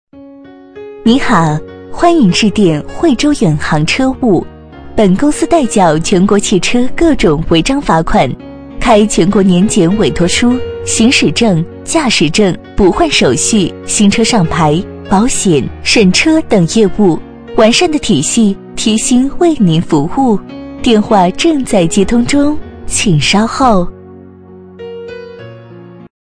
【女36号抒情】彩铃
【女36号抒情】彩铃.mp3